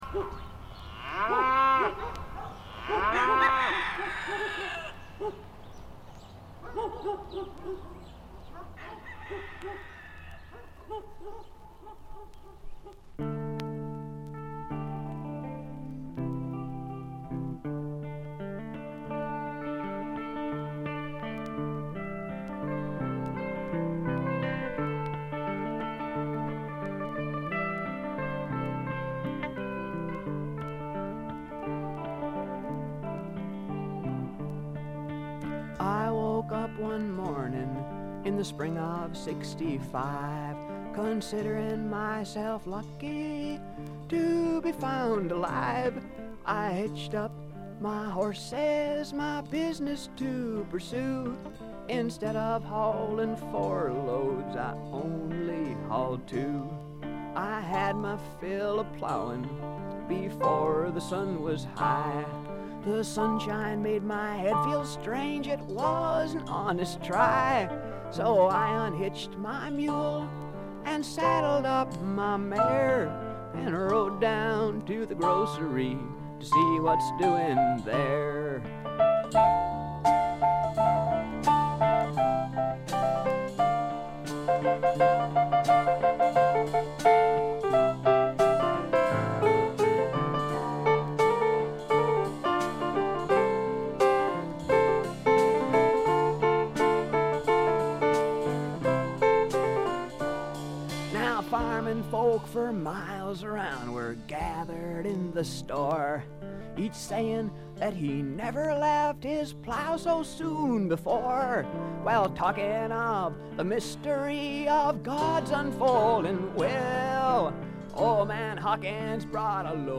軽微なバックグラウンドノイズ。散発的なプツ音が少し。
試聴曲は現品からの取り込み音源です。